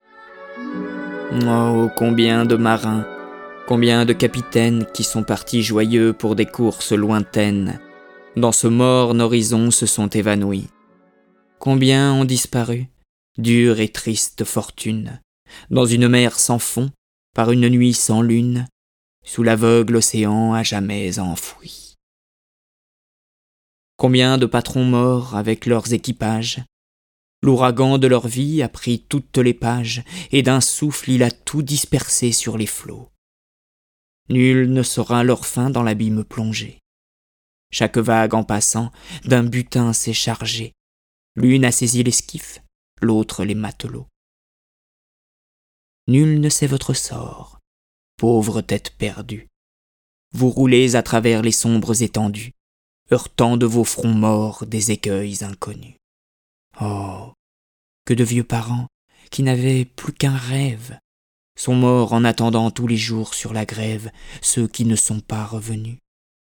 Le récit et les dialogues sont illustrés avec les musiques de Beethoven, Borodine, Chopin, Corelli, Debussy, Dvorak, Grieg, Mozart, Pergolèse, Rimsky-Korsakov, Schubert, Tchaïkovski, Telemann et Vivaldi.